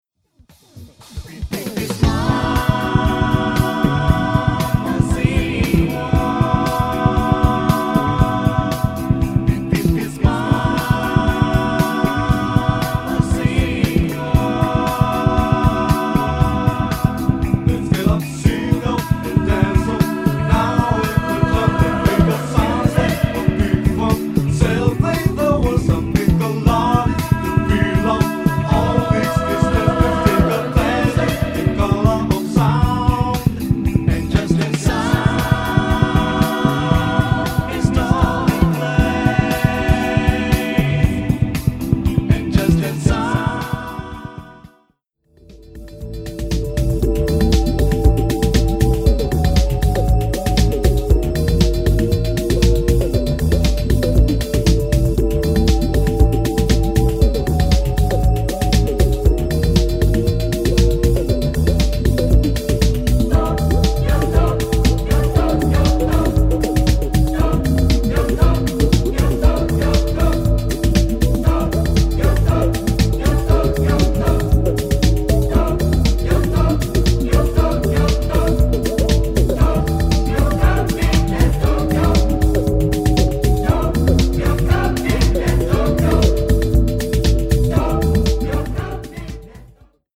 エスノ・エレクトロニクス・ダンス・グルーヴ